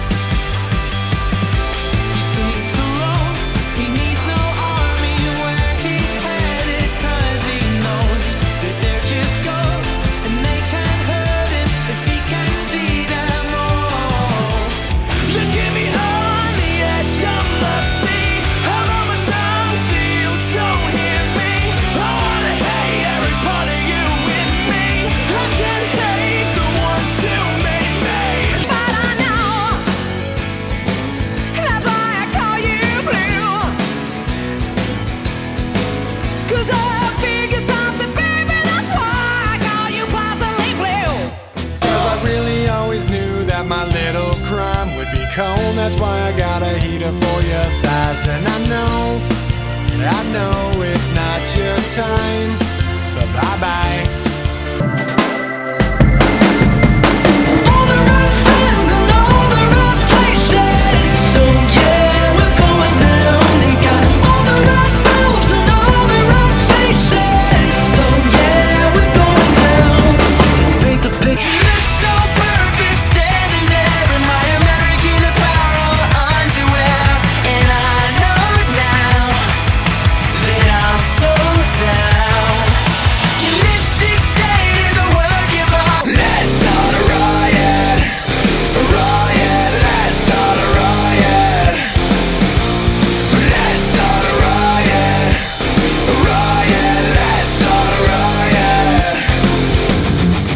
The sound given is a clip of 7 songs.